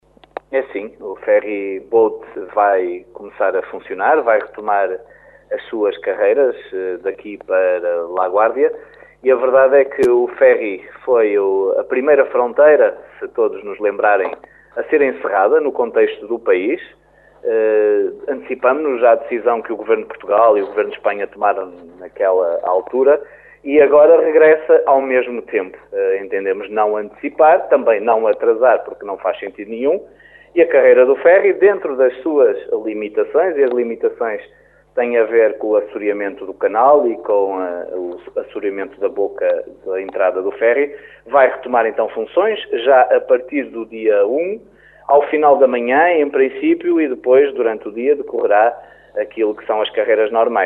O Ferryboat que faz a ligação entre Caminha e A Guarda também começa a funcionar esta quarta-feira como avançou à Rádio Caminha o presidente da Câmara de Caminha, Miguel Alves.